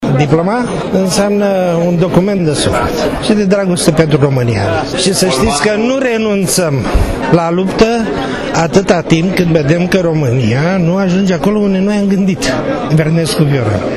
diplomaPrimăria Capitalei a acordat diplome revoluționarilor din 1989, în cadrul unei festivități.
De partea cealaltă, unii revoluţionari au păstrat viu spiritul de luptător: